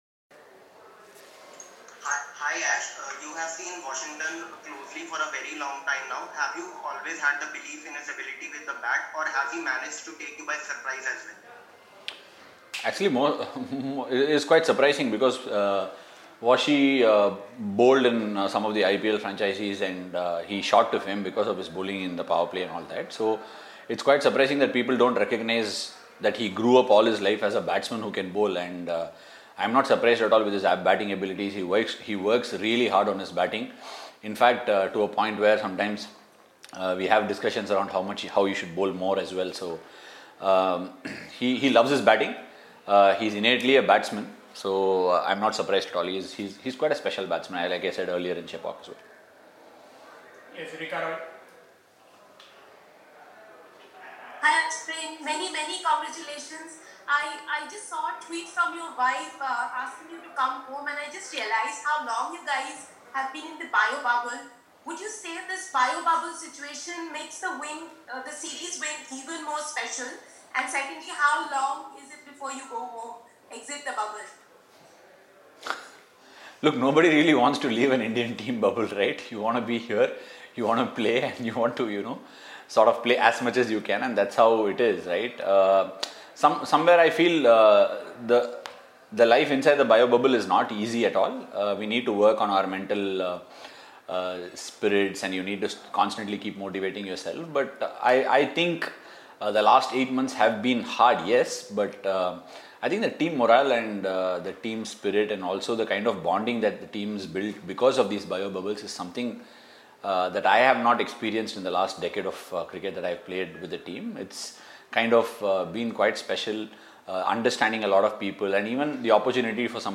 R. Ashwin, addressed a virtual press conference after the fourth Paytm India-England Test
R. Ashwin, Member, Indian Cricket Team, addressed a virtual press conference after India booked their place in the World Test Championship final with a comprehensive innings and 25 runs win over England at Motera in Ahmedabad on Saturday.